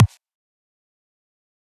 EDM Kick 37.wav